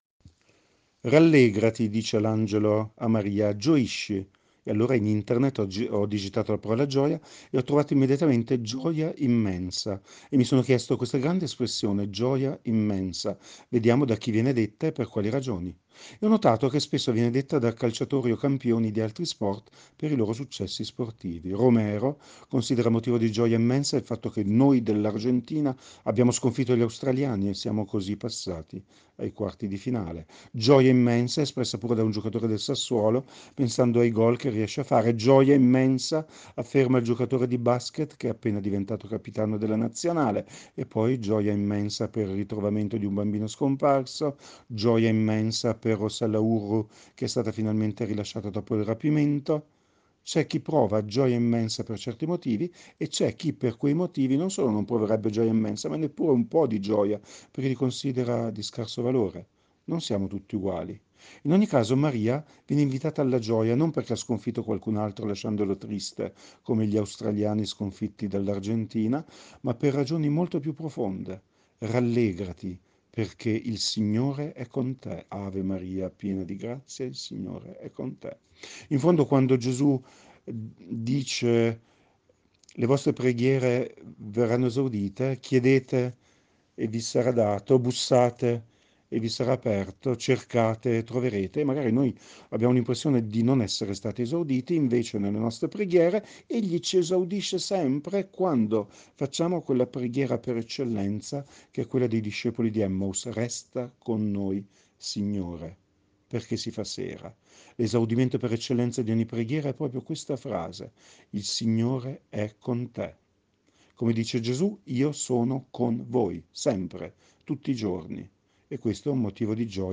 Meditazione Immacolata Concezione 8 dicembre 2022 – Parrocchia di San Giuseppe Rovereto